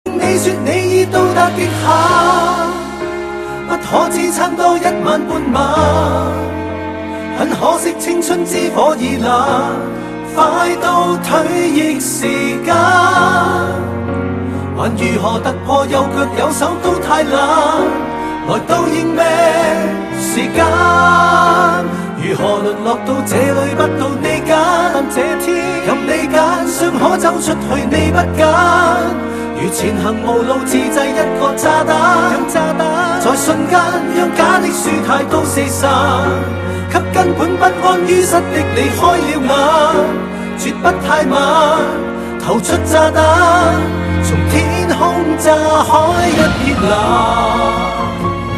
华语歌曲
粤语